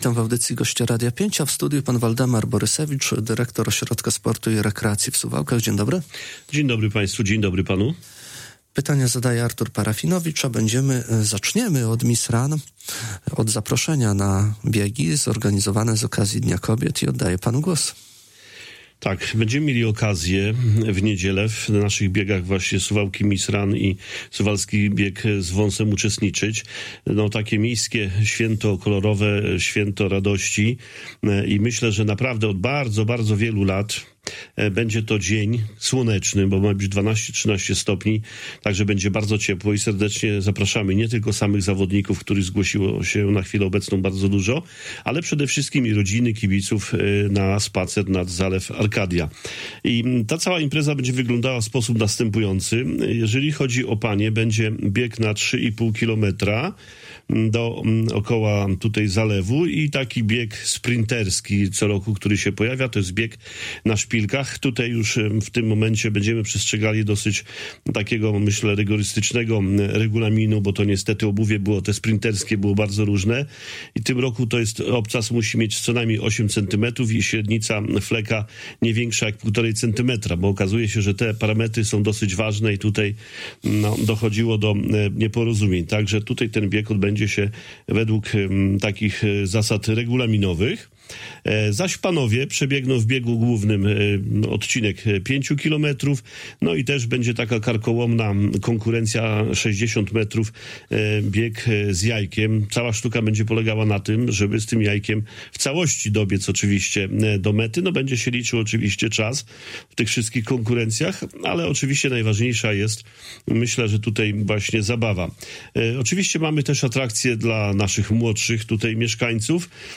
Pełne nagranie rozmowy poniżej: